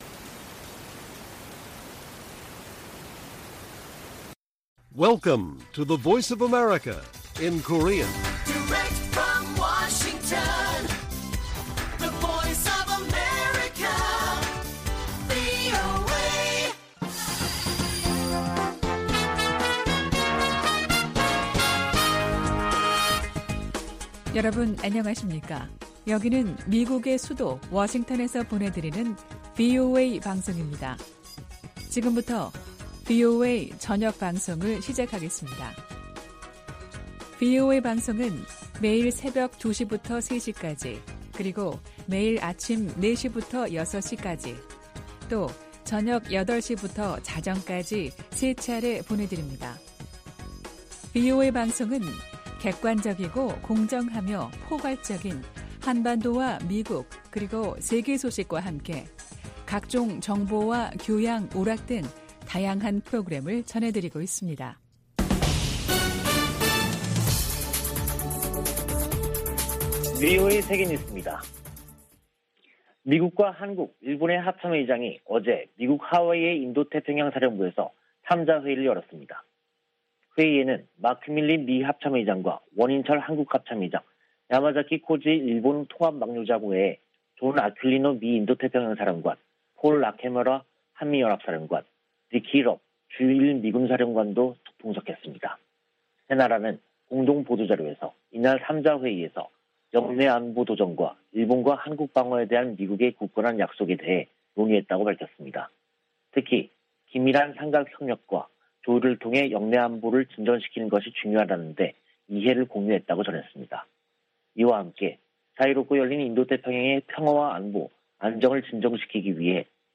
VOA 한국어 간판 뉴스 프로그램 '뉴스 투데이', 2022년 3월 31일 1부 방송입니다. 북한이 최근 ICBM을 발사한 곳이 평양 순안공항 인근 미사일 기지에서 멀지 않은 곳으로 확인됐습니다. 북한이 최근 발사한 ICBM이 화성 17형이 아닌 화성 15형이라고 한국 국방부가 밝힌 가운데 미 국방 당국은 여전히 분석 중이라는 입장을 내놨습니다. 북한에서 6개월 안에 식량상황 악화 등 위기가 발생할 가능성이 있다고 스위스 비정부기구가 전망했습니다.